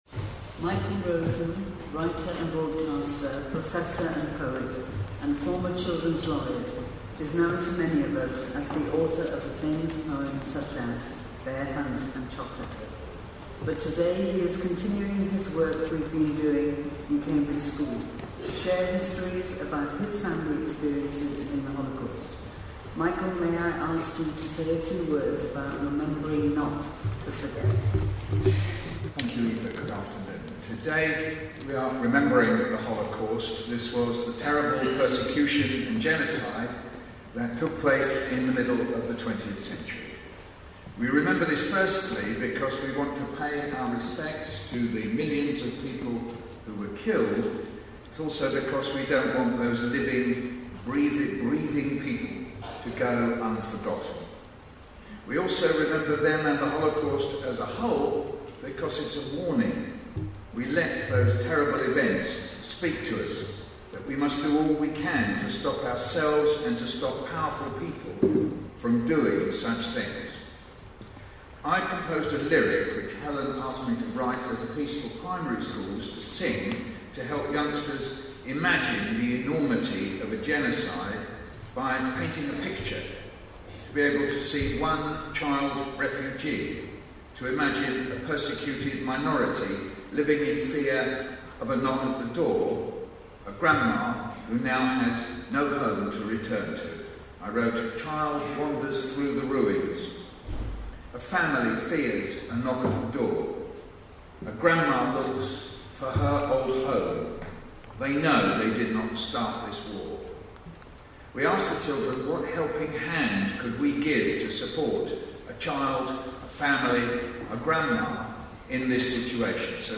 The poet, Michael Rosen, talks about the numerous reasons we need to remember and mourn the enormity of the Holocaust & subsequent genocides.